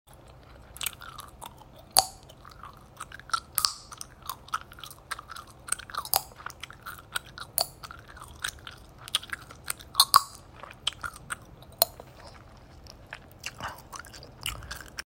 Pimple Popping And Gum Chewing Sound Effects Free Download